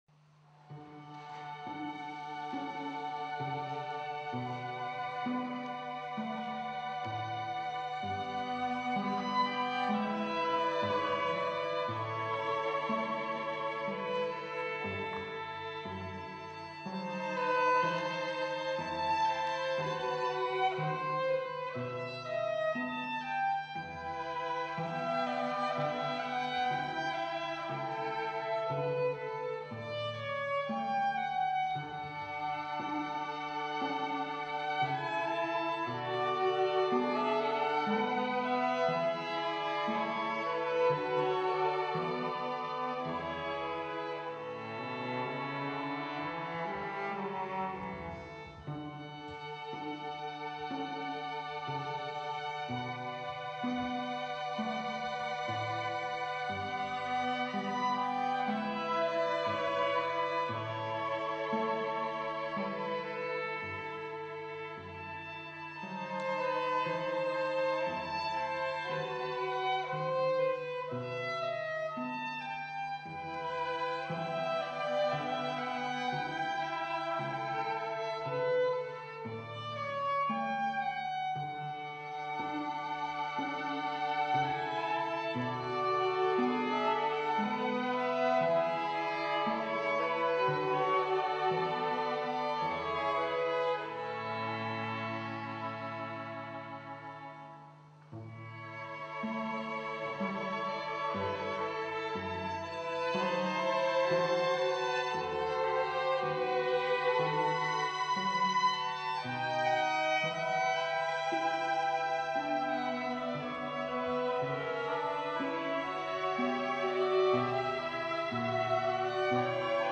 violino
viola
violoncello